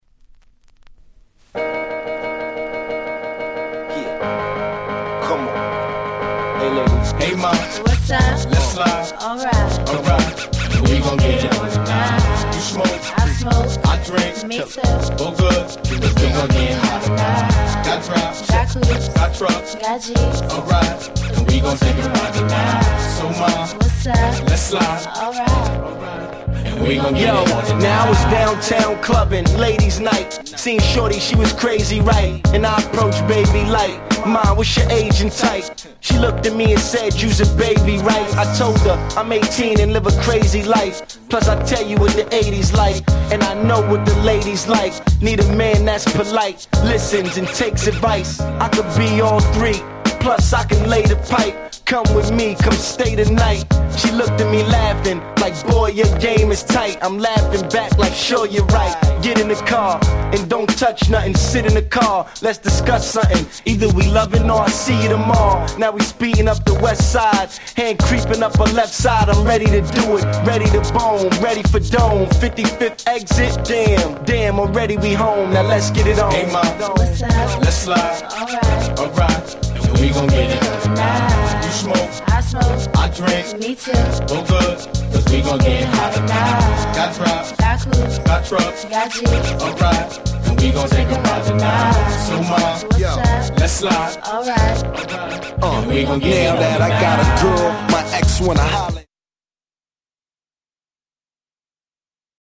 02' 大Hit Hip Hop !!